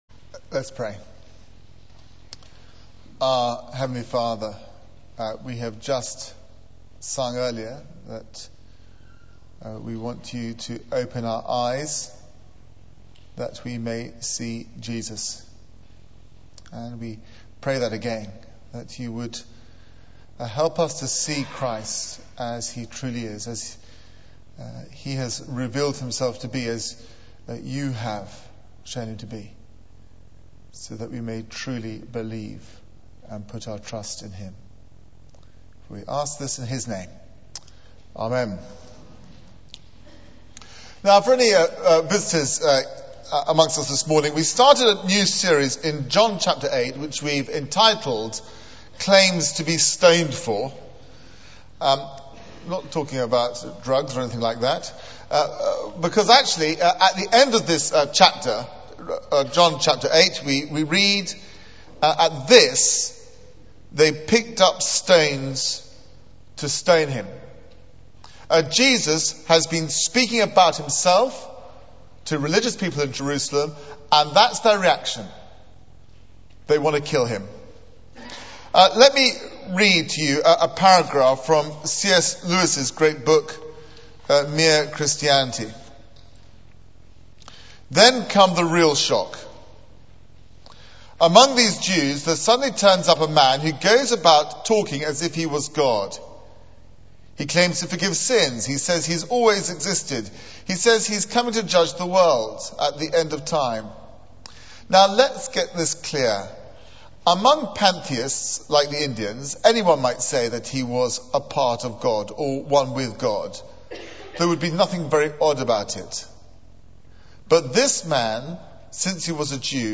Media for 9:15am Service on Sun 03rd May 2009 09:15 Speaker: Passage: John 8:21-30 Series: Claims to be Stoned For Theme: The Son of the Father Sermon Search the media library There are recordings here going back several years.